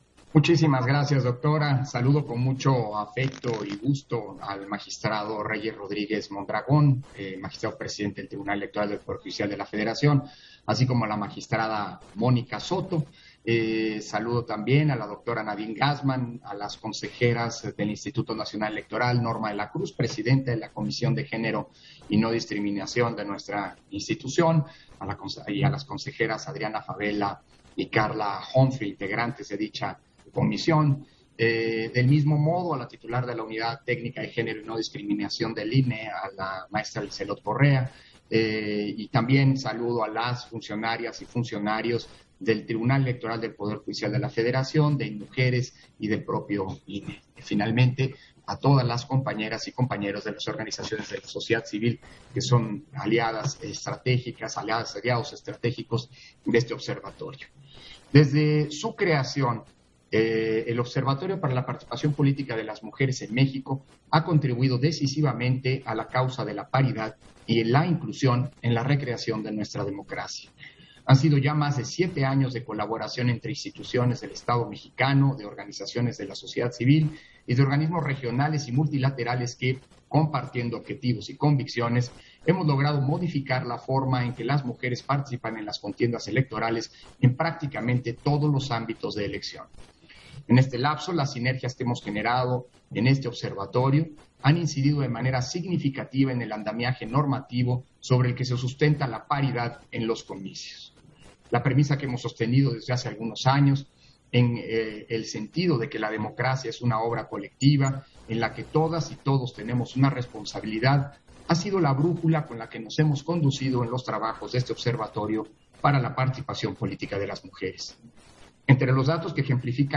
Intervención de Lorenzo Córdova, en la sesión protocolaria, de entrega de Presidencia y Secretaría Técnica del Observatorio de Participación Política de las Mujeres en México